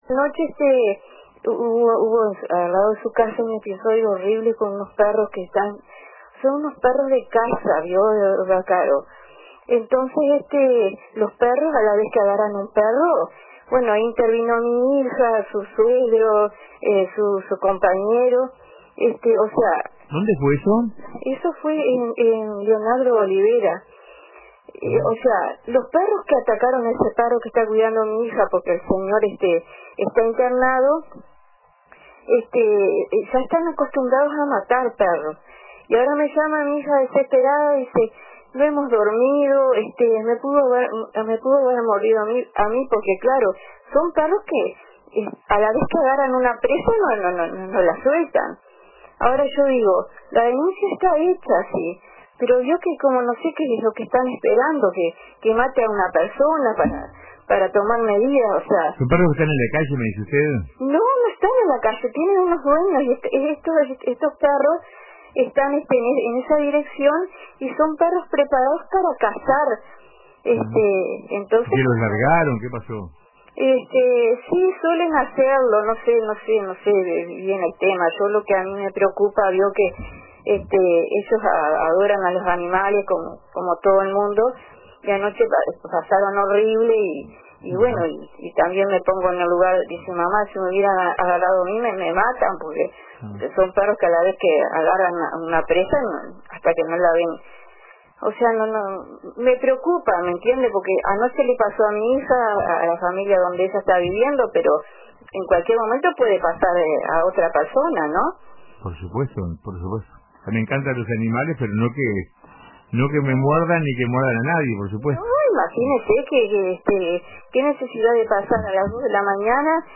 Una oyente del programa Pan de Azúcar en Sintonía, de RADIO RBC, compartió una preocupante situación relacionada con perros de caza.